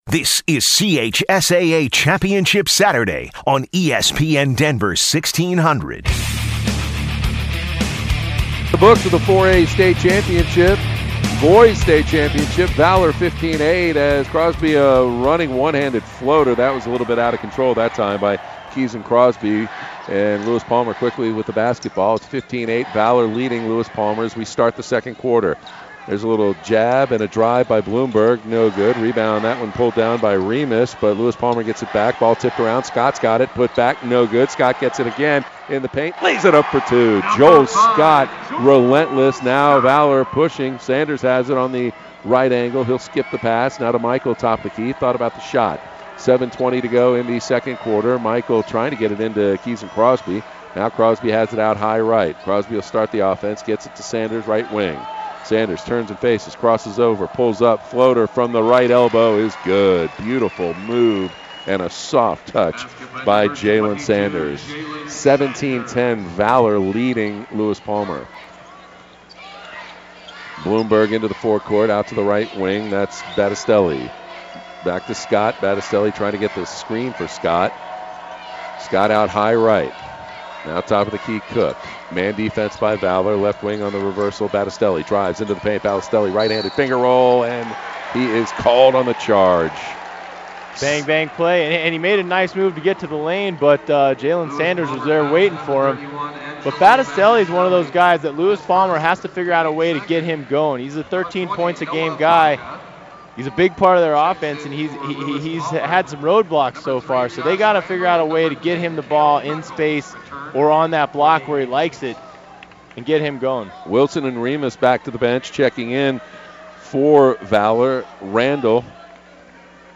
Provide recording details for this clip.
Quarter 2 - CHSAA Championship Saturday: 4A Boys Final - Lewis-Palmer vs. Valor Christian